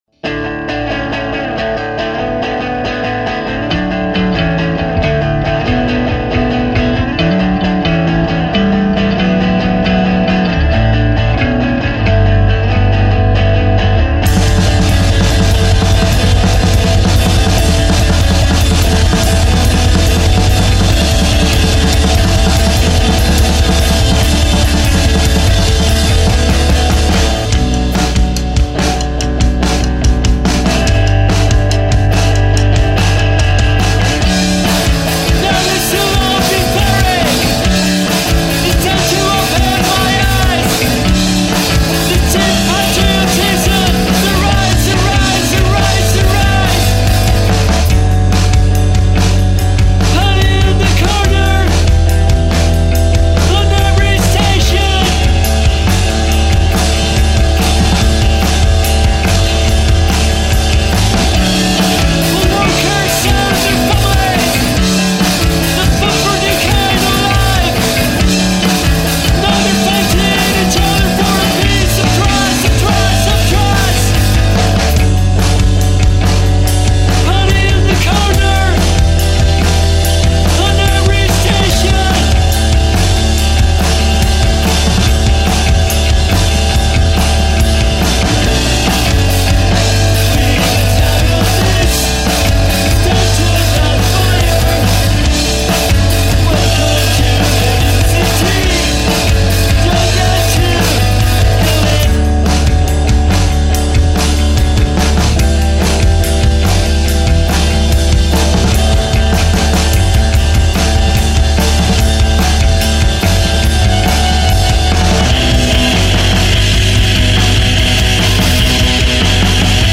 Madrid Spain based post-hardcore & emo band